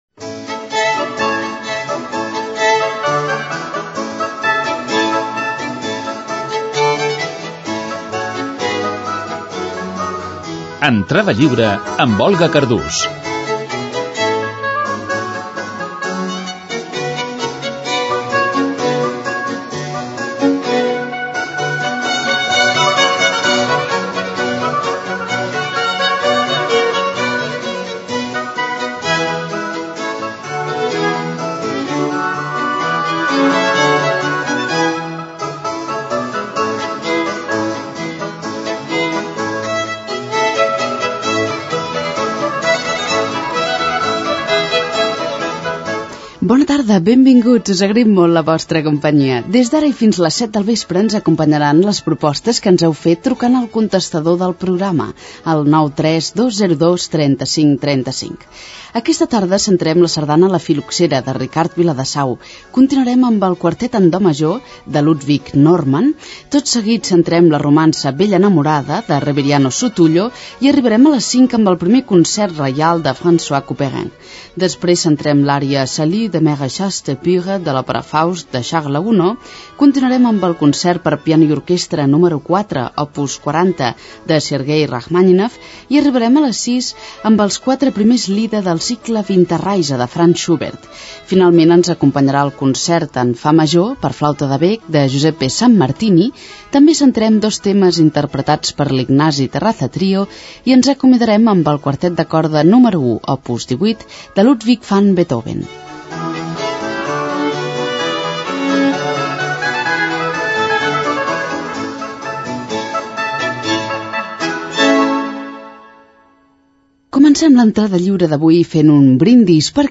Careta del programa, telèfon de contacte del programa, sumari de continguts i presentació d'una sardana
Musical
FM